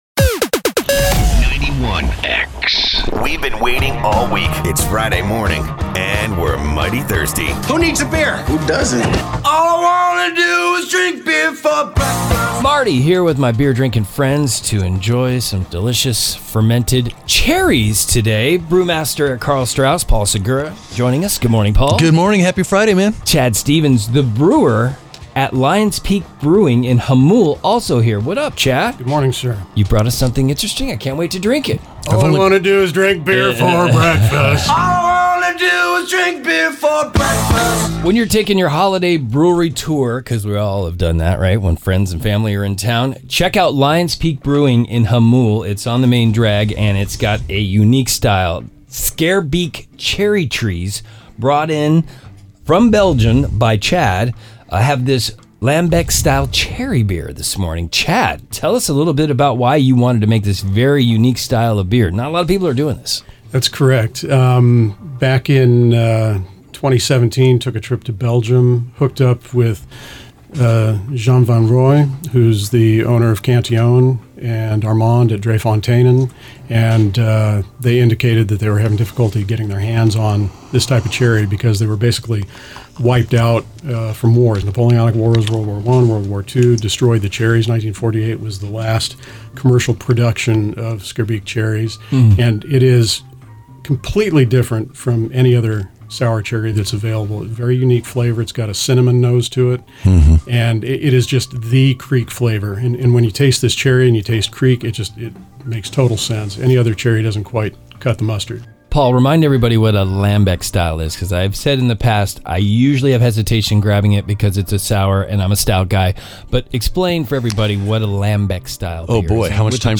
but that’s just what this week’s in-studio guest